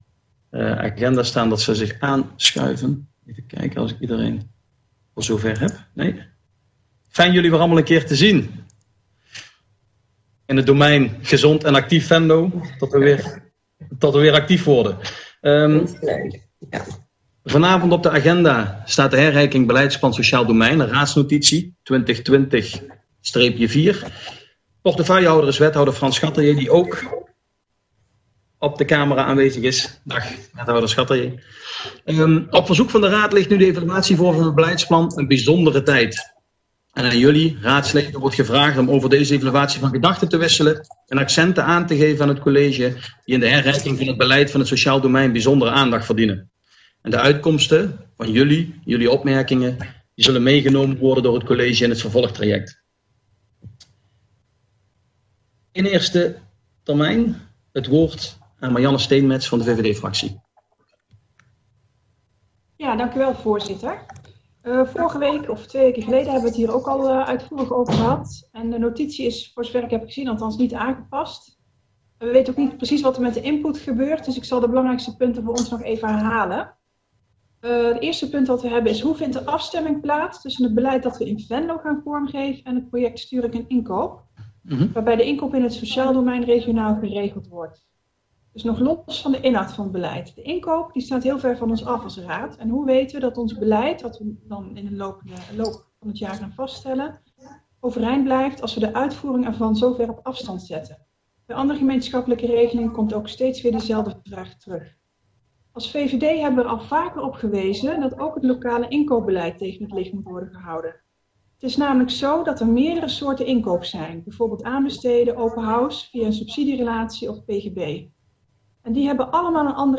Oordeelsvormende raadsvergadering 10 juni 2020 19:15:00, Gemeente Venlo
Locatie: Stadhuis Parterre